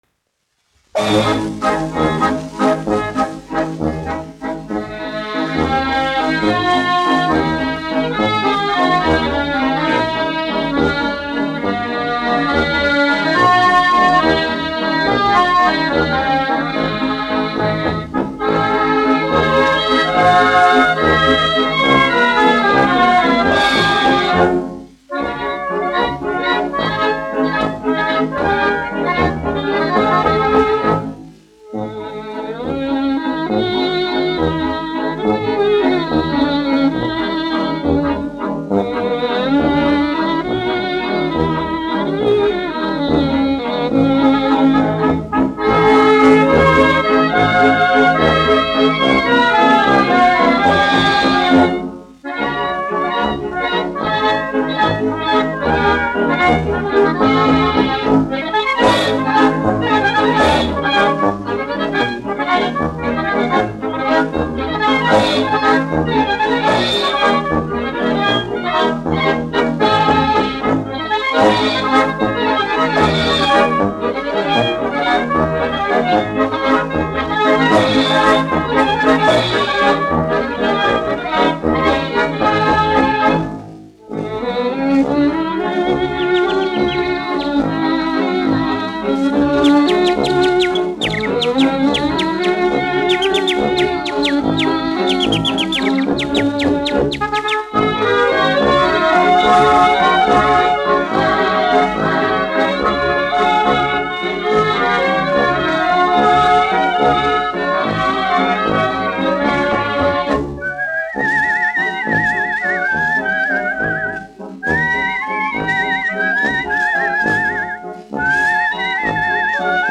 1 skpl. : analogs, 78 apgr/min, mono ; 25 cm
Valši
Populārā instrumentālā mūzika
Latvijas vēsturiskie šellaka skaņuplašu ieraksti (Kolekcija)